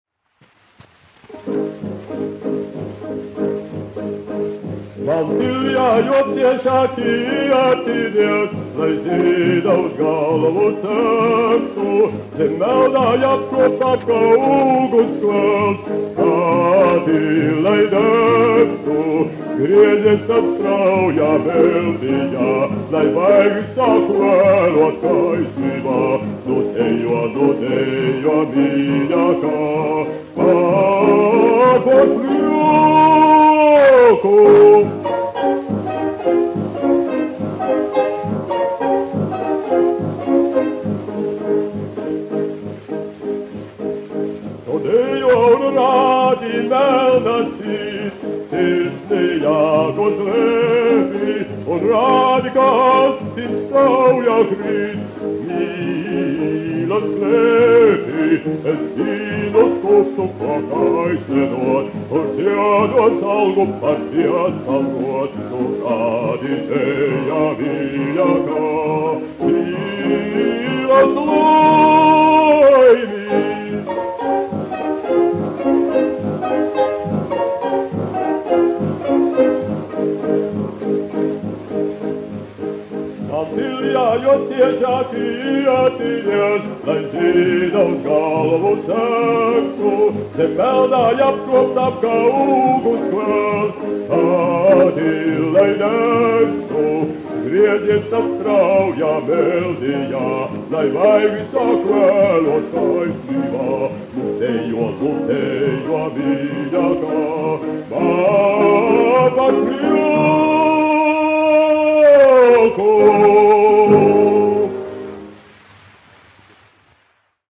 1 skpl. : analogs, 78 apgr/min, mono ; 25 cm
Operas--Fragmenti
Skaņuplate
Latvijas vēsturiskie šellaka skaņuplašu ieraksti (Kolekcija)